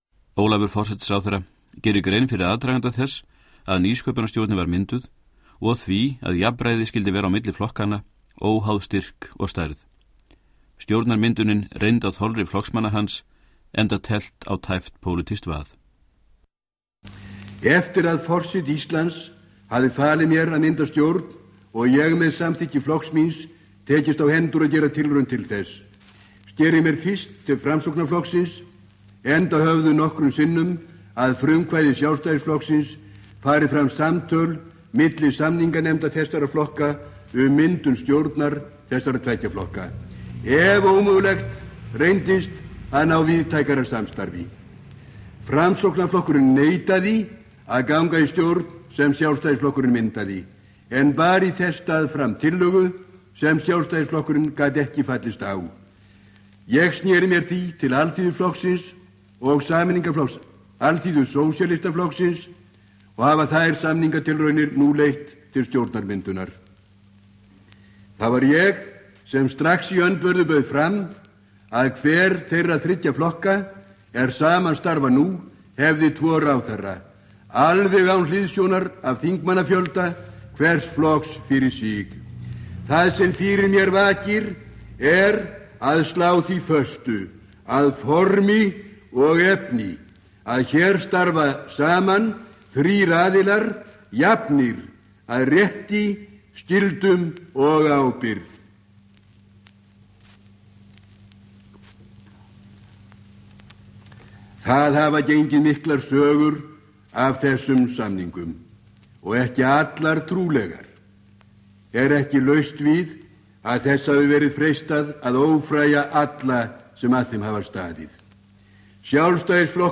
Upptökurnar af ræðum Ólafs Thors voru fengnar hjá safndeild Ríkisútvarpsins með góðfúslegu leyfi stofnunarinnar.
Ræða flutt eftir myndun Nýsköunarstjórnar 1944.